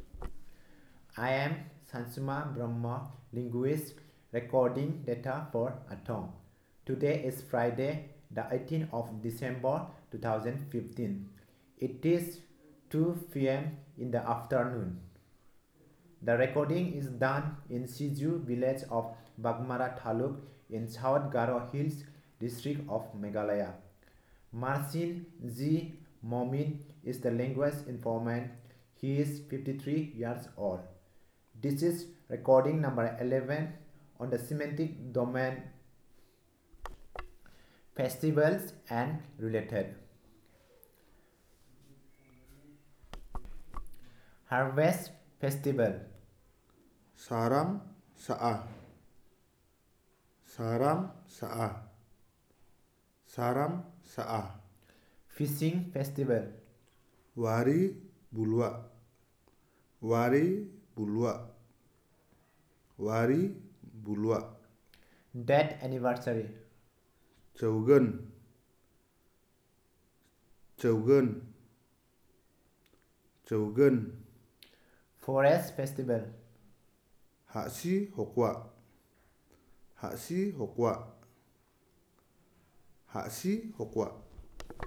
Elicitation of words about festivals and related